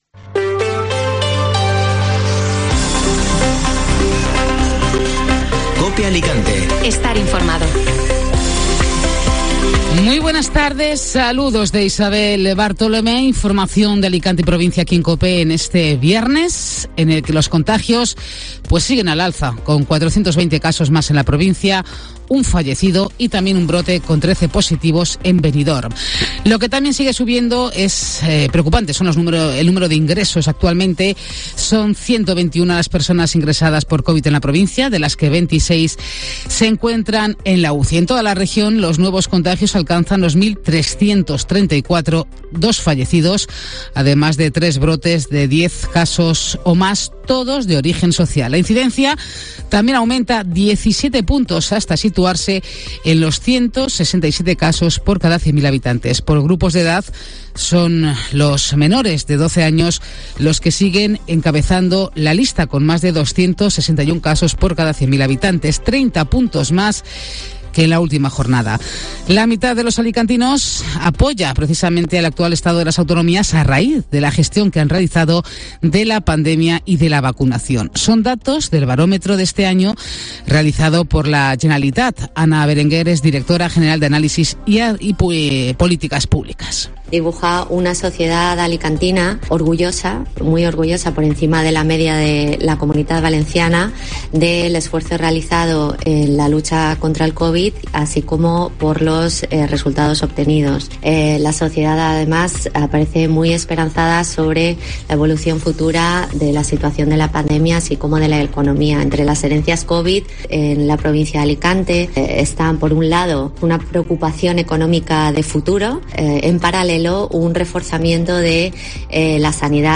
Informativo Mediodía COPE (Viernes 26 de noviembre)